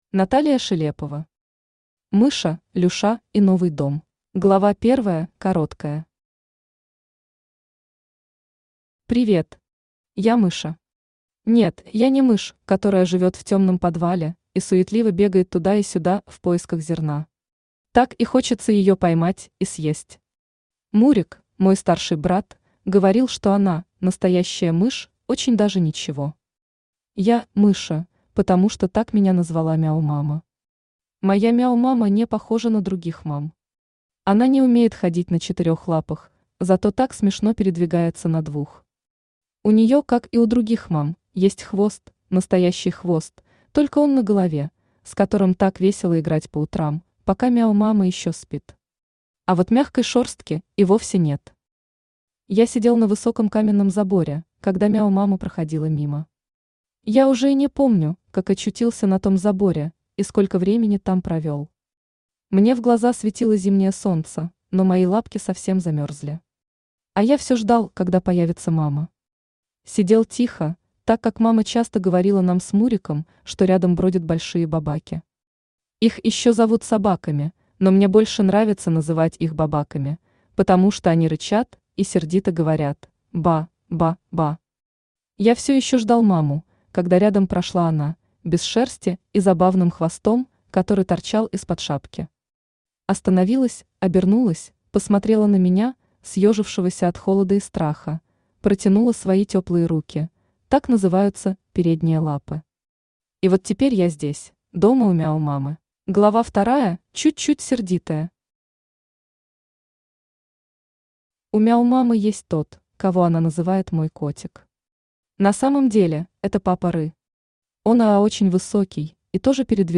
Аудиокнига Мыша, Люша и новый дом | Библиотека аудиокниг
Aудиокнига Мыша, Люша и новый дом Автор Наталия Шелепова Читает аудиокнигу Авточтец ЛитРес.